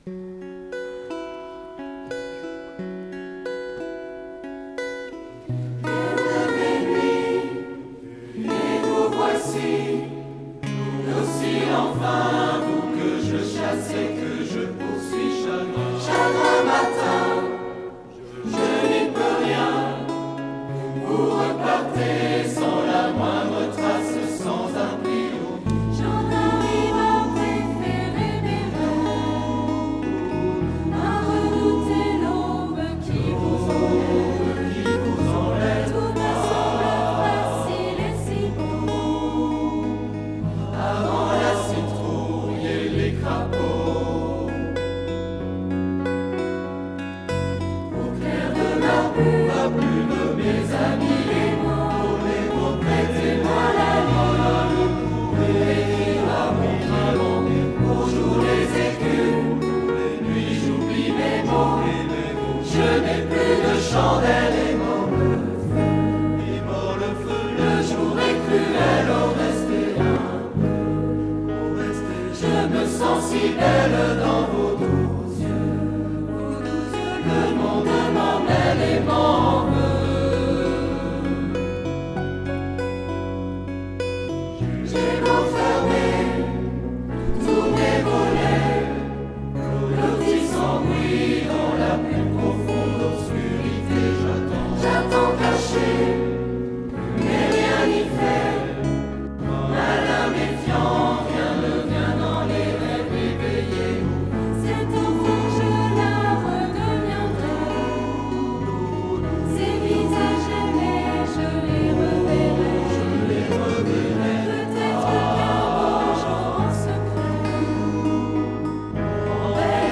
Ensemble Vocal et Instrumental
Veillée de Noël 2011 "J'ai un rêve" Texte complet Photos